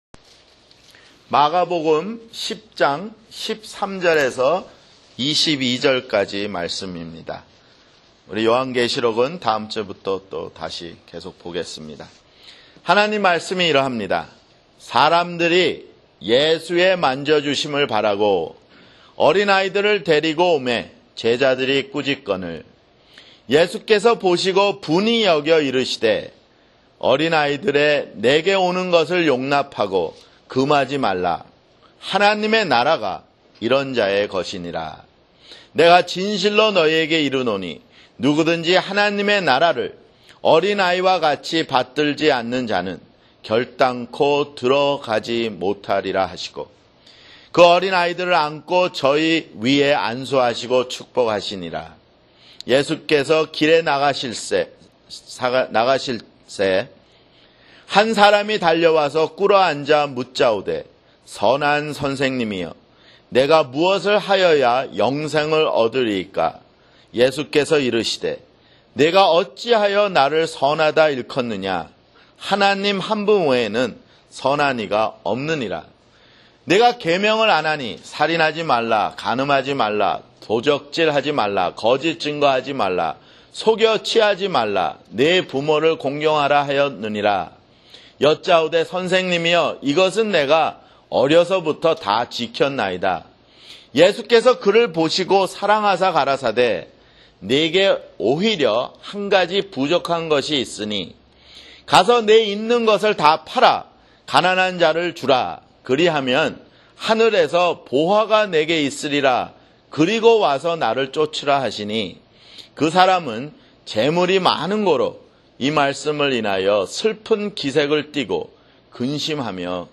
[주일설교] 마가복음 10:13-22